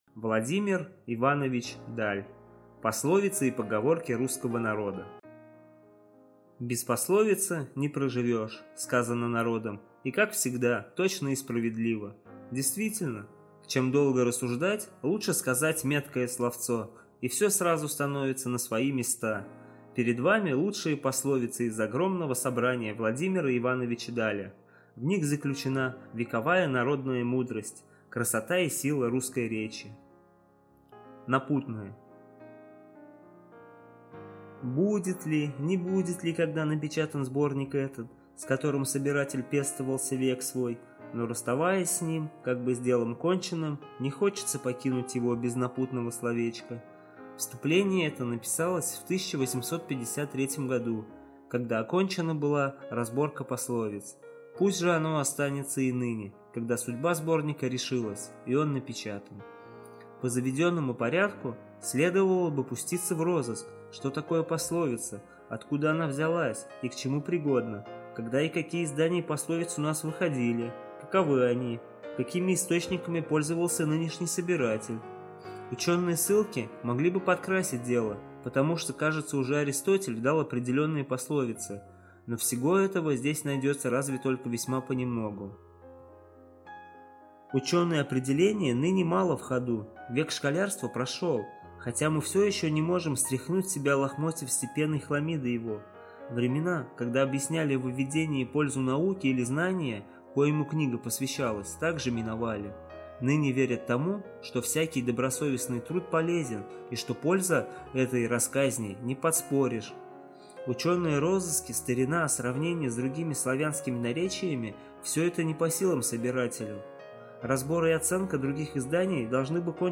Аудиокнига Пословицы и поговорки русского народа | Библиотека аудиокниг